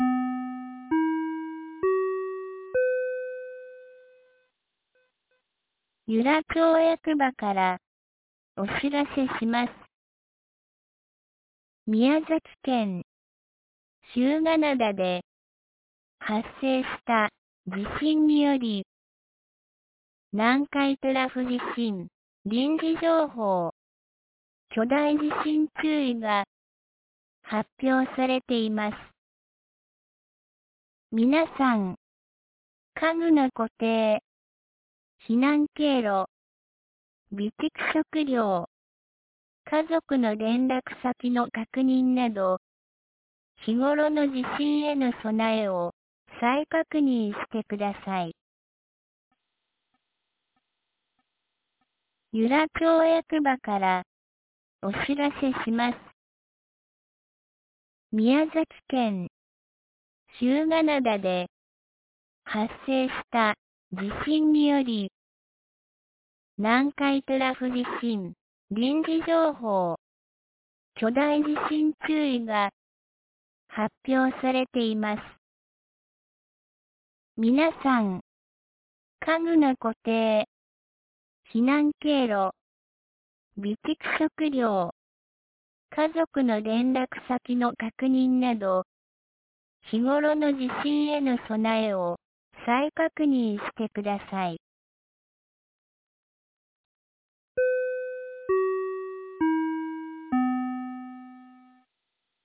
2024年08月14日 12時22分に、由良町から全地区へ放送がありました。